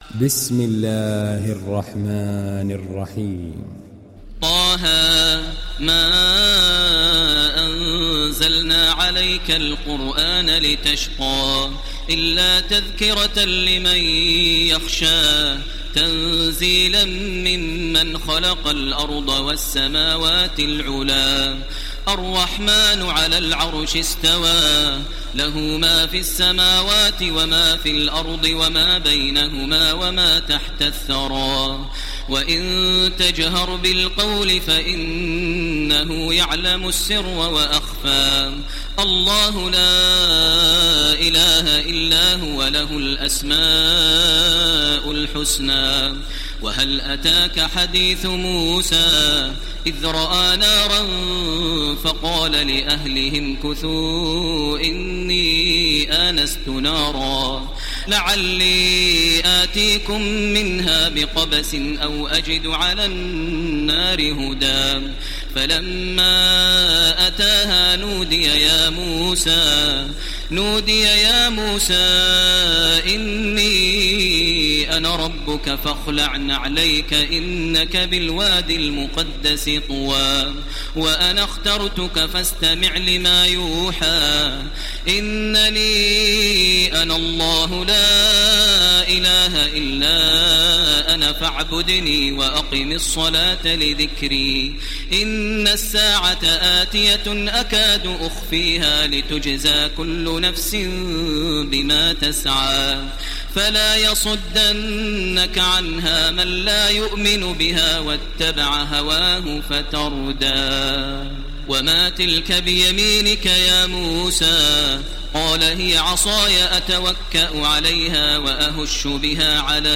ডাউনলোড সূরা ত্বা-হা Taraweeh Makkah 1430